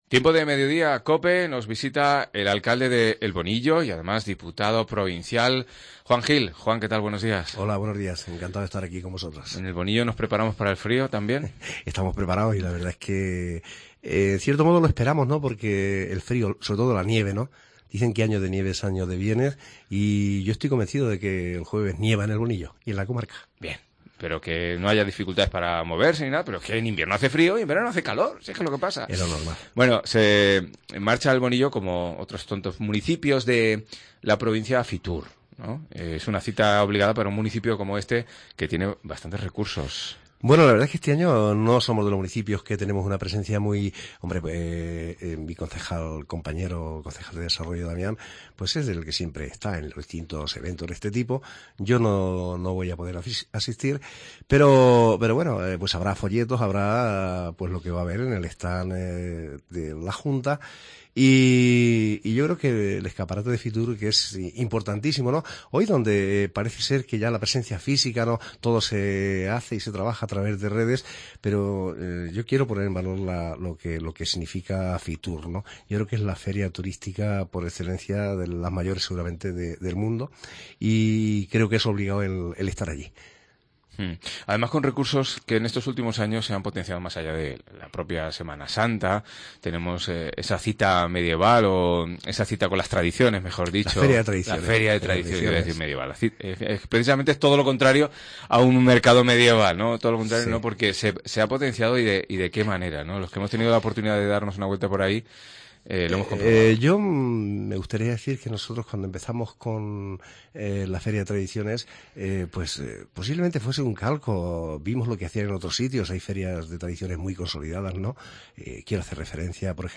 170117 Entrevista Juan Gil alcalde El Bonillo